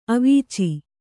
♪ avīci